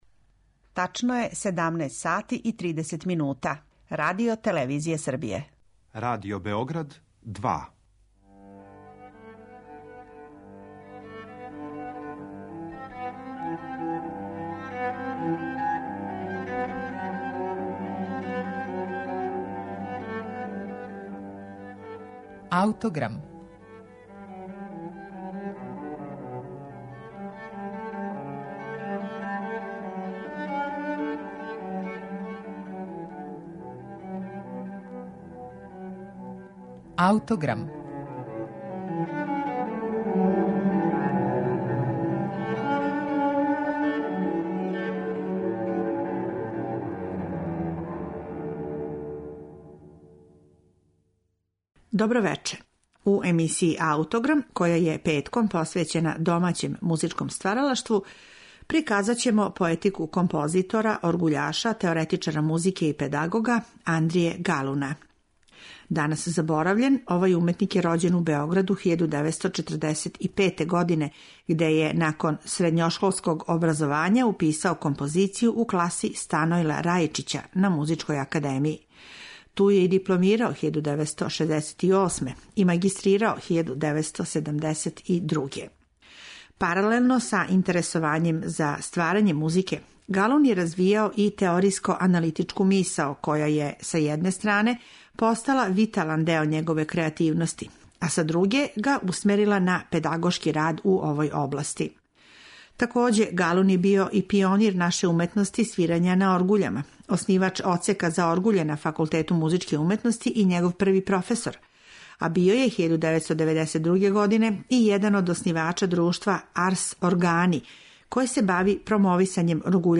Јавни снимак овог дела остварен је 1974. године.